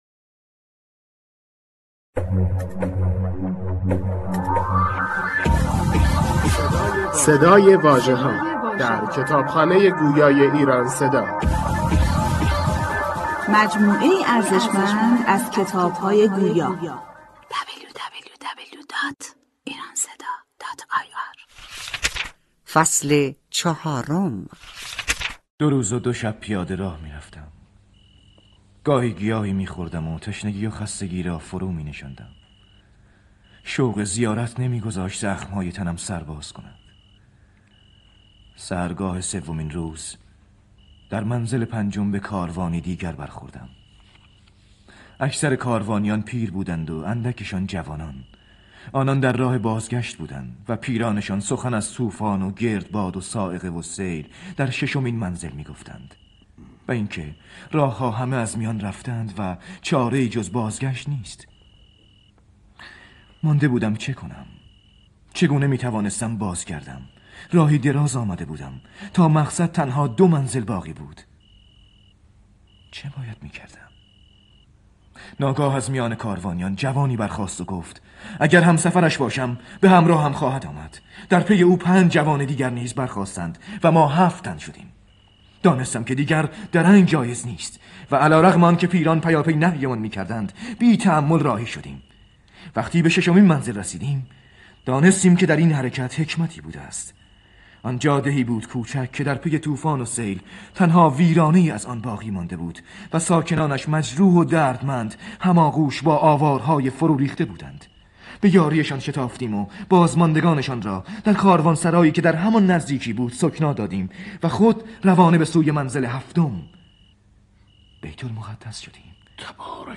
فصل چهارم کتاب گویا بُشر و ملیخا، روایت‌گر بازگشت بُشر از بیت‌المقدس به سرزمین خود و مواجهه دوباره با تمنای نخستین است. آزمونی نهایی میان عشق و پرهیز، با فضاسازی صوتی عرفانی و اجرای بازیگران برجسته.